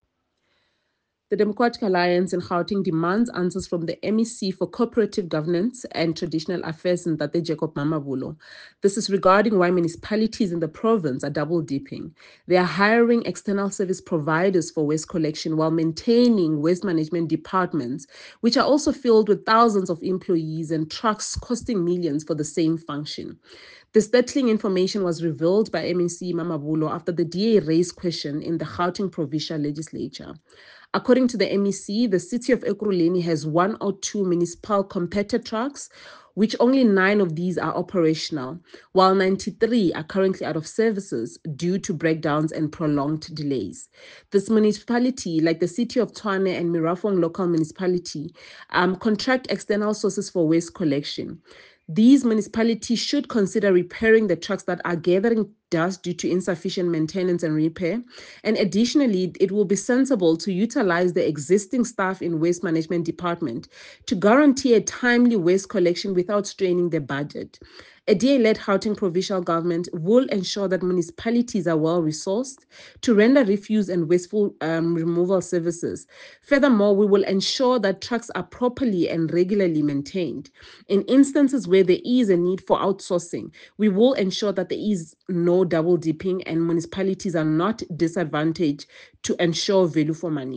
soundbite by Khathutshelo Rasilingwane MPL.